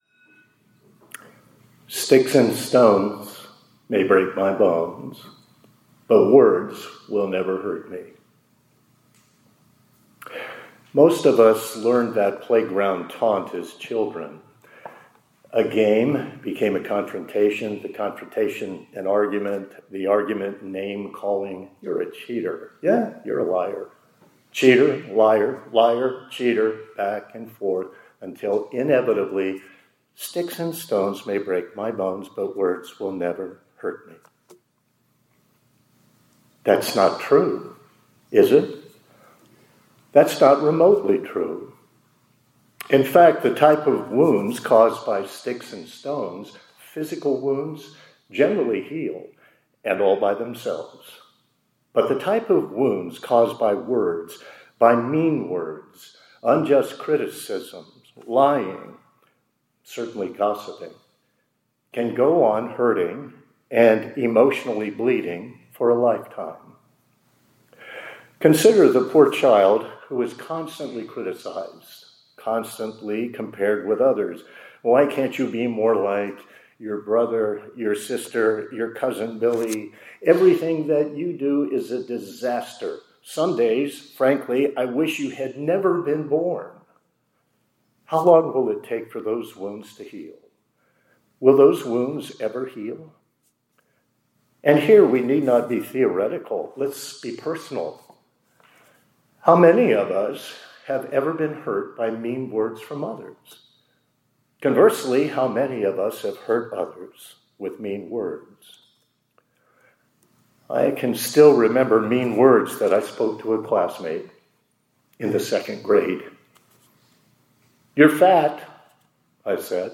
2025-10-10 ILC Chapel — Sticks and Stones